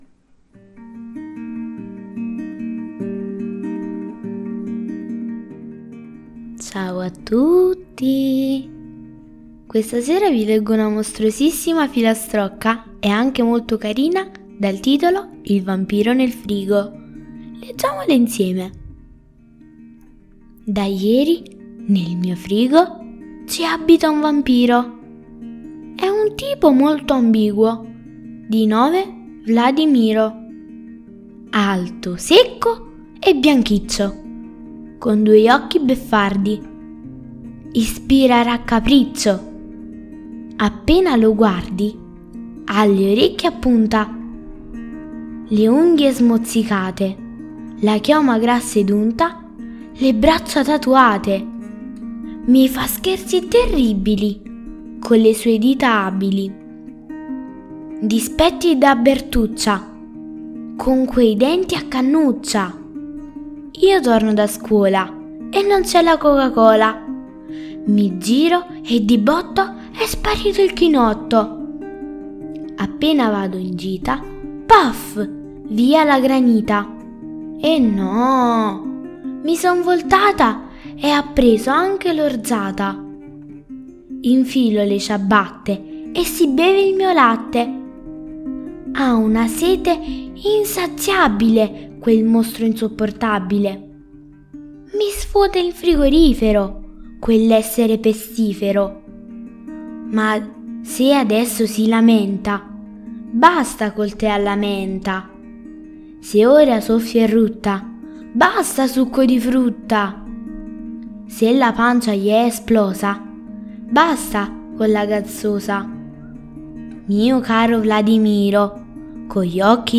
Leggi e ascolta “Il vampiro nel frigo” la divertente e spaventosa filastrocca di Halloween
mamma legge la fiaba